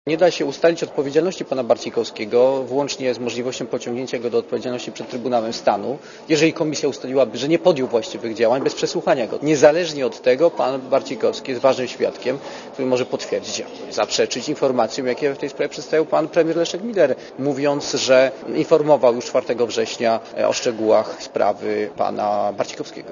© (RadioZet) Mówi Zbigniew Ziobro (90Kb)